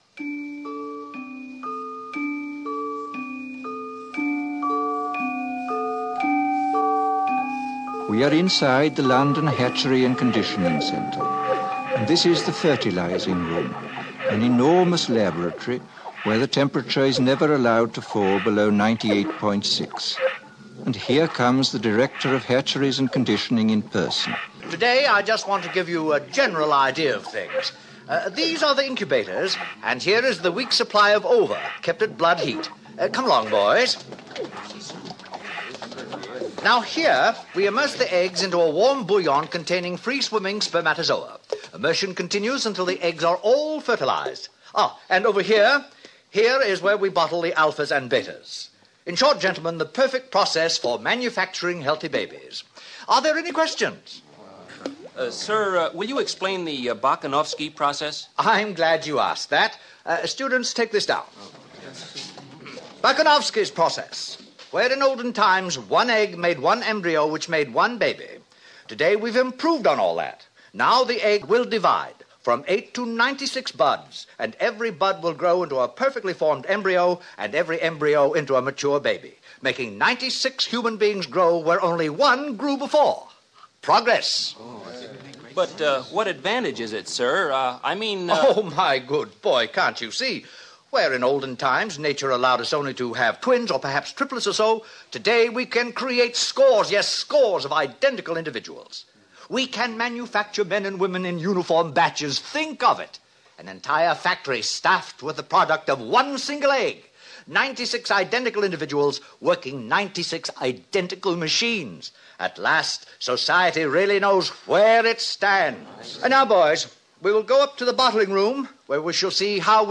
I'm having problems with my voice so today Aldous Huxley will do the talking.